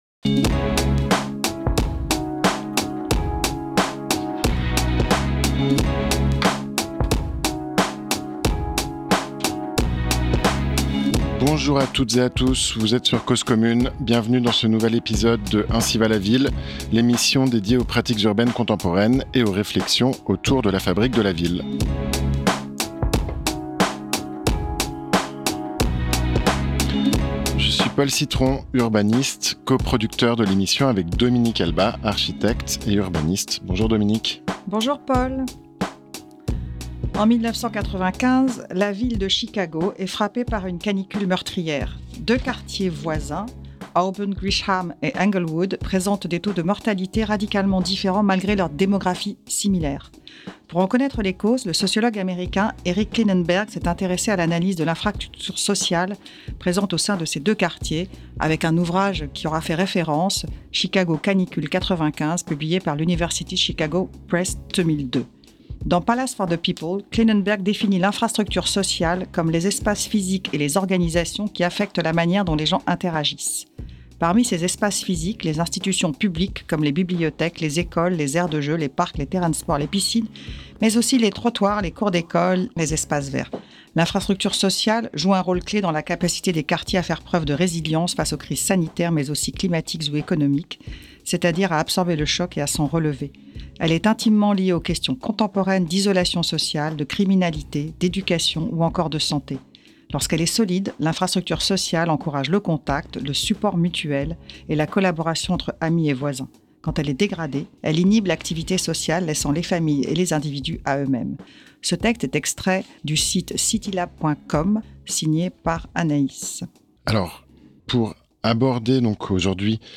🌆 Une discussion passionnante autour de la ville productive , des initiatives citoyennes et des nouvelles pratiques de l’urbanisme.